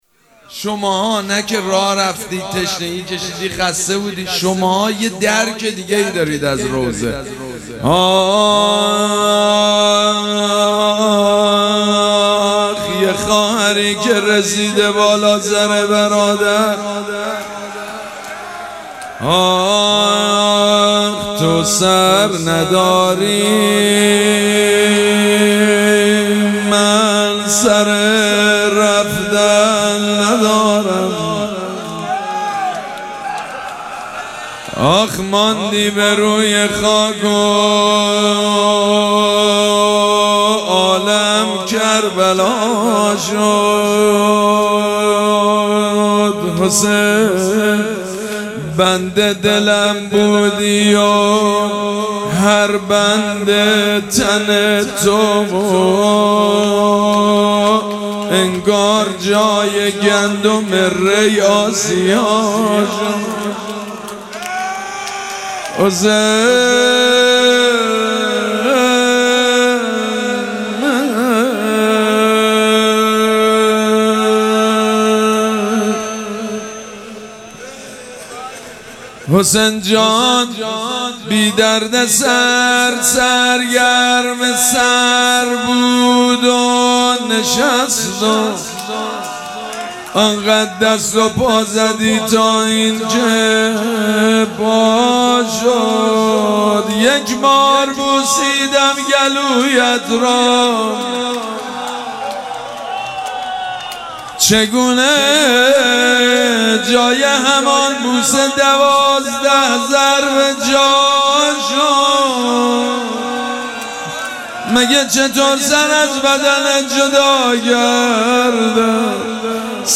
شب اول مراسم عزاداری اربعین حسینی ۱۴۴۷
روضه
مداح
حاج سید مجید بنی فاطمه